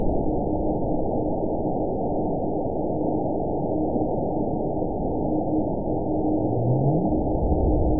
event 920559 date 03/30/24 time 00:36:13 GMT (1 year, 1 month ago) score 9.46 location TSS-AB02 detected by nrw target species NRW annotations +NRW Spectrogram: Frequency (kHz) vs. Time (s) audio not available .wav